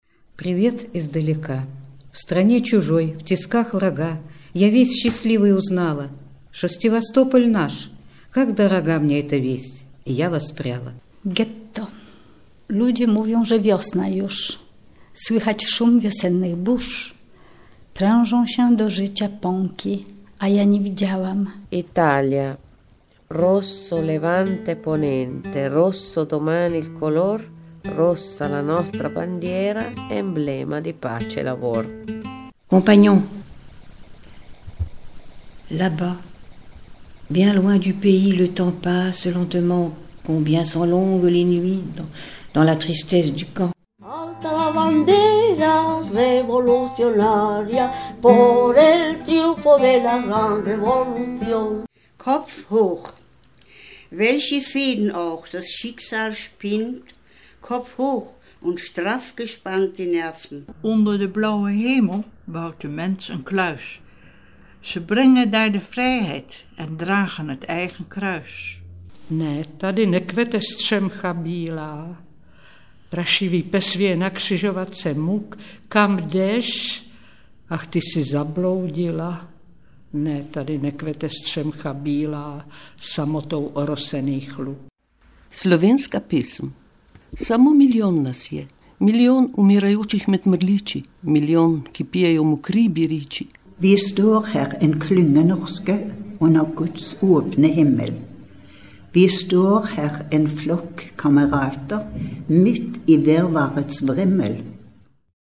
Die Tatsache, dass 17 Frauen für Aufnahmen in 11 Sprachen gewonnen werden konnten, unterstreicht die Bedeutung des Vorhabens auch aus der Sicht der Betroffenen.
Hörbeispiel Mp3 Stimmen Überlebender international